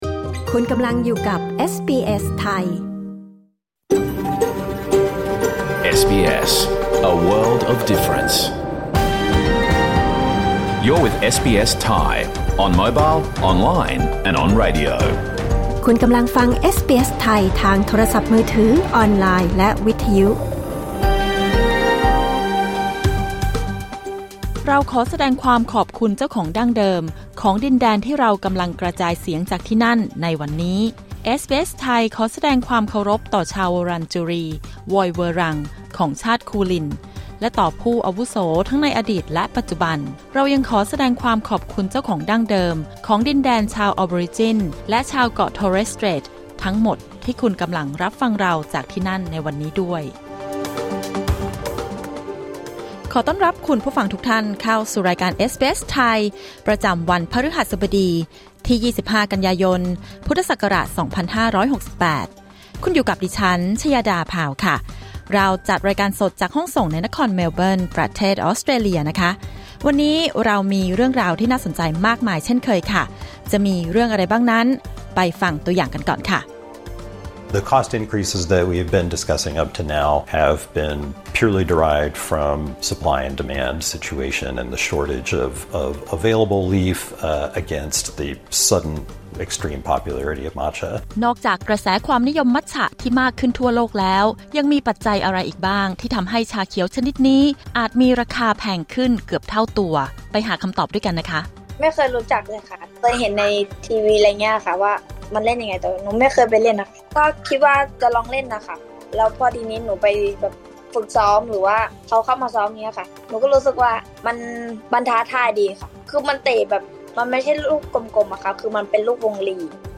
รายการสด 25 กันยายน 2568